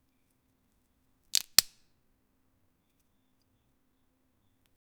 opening-precut-avocado-t3yfocvk.wav